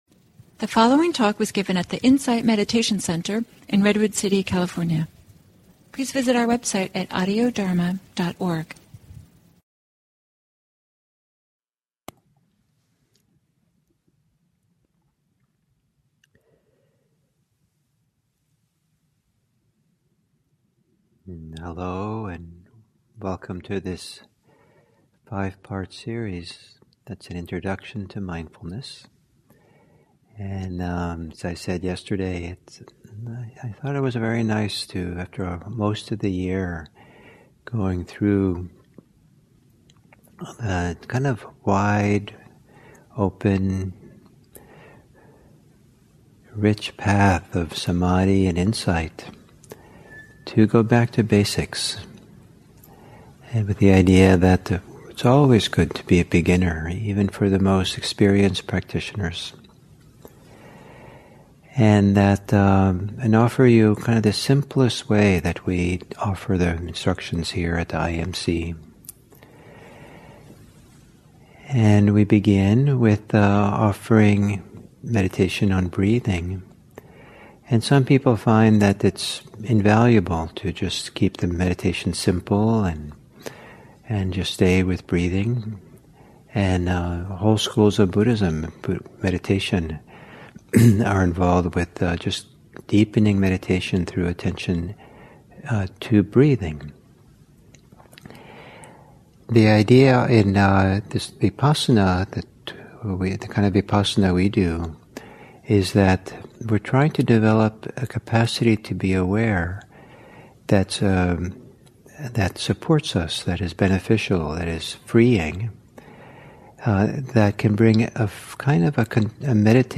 Dharmette: Love (7) Mindful with Deep Love. Guided Meditation: Aware with Love.